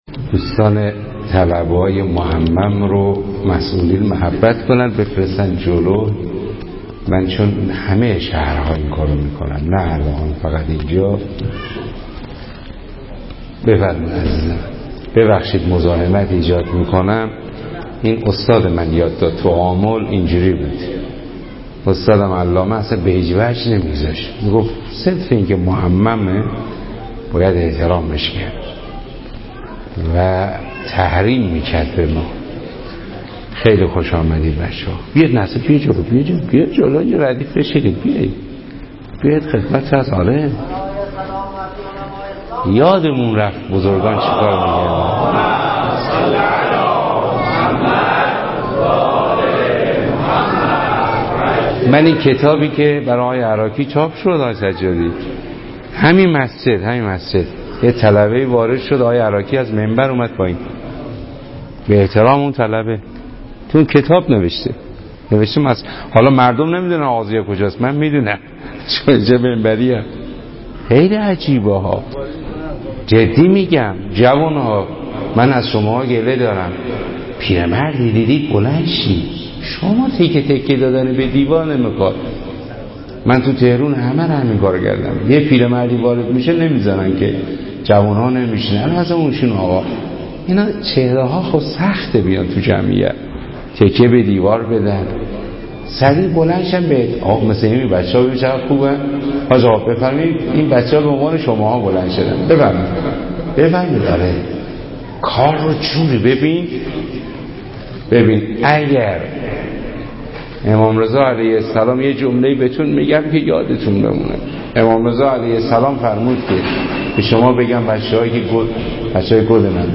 صوت سخنرانی
در دهه اول محرم 1393 در مسجد آقاضیاء الدین شهر اراک برگزار می‌شود.